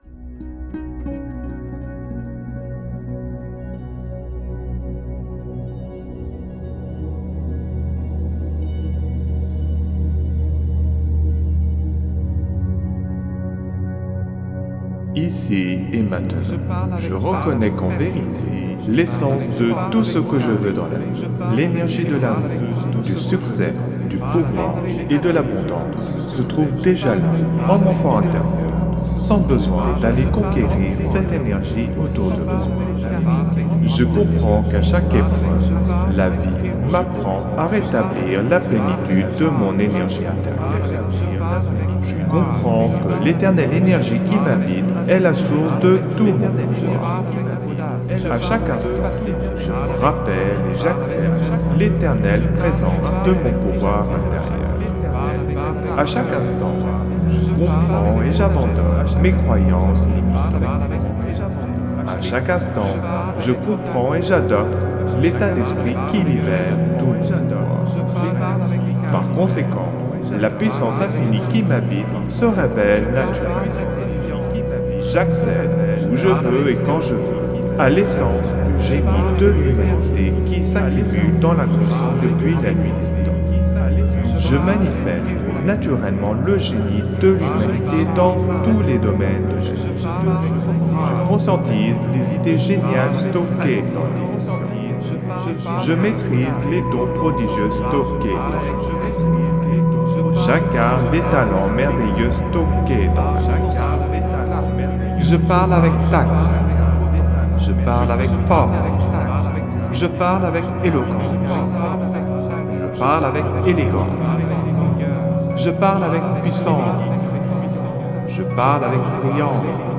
Alliage ingénieux de sons et fréquences curatives, très bénéfiques pour le cerveau.
Pures ondes gamma intenses 61,53 Hz de qualité supérieure. Puissant effet 3D subliminal écho-guidé.
SAMPLE-Orateur-brillant-echo.mp3